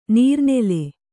♪ nīrnele